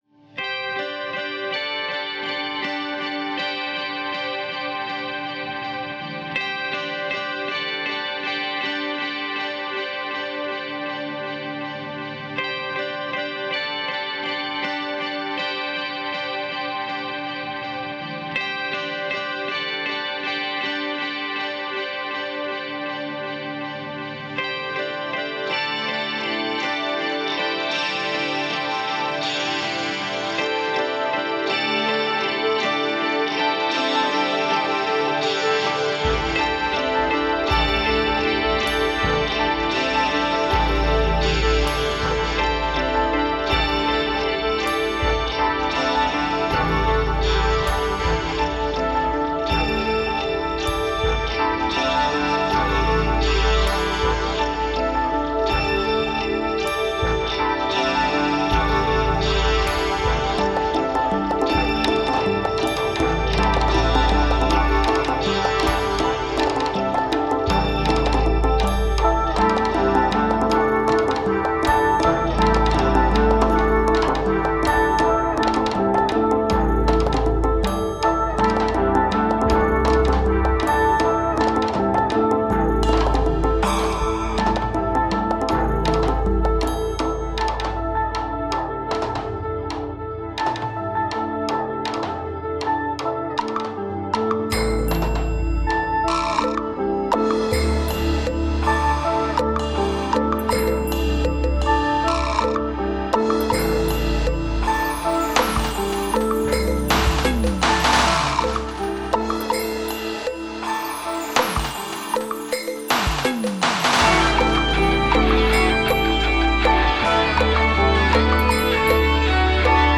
Ambient Trance Prog aus Offenbach aM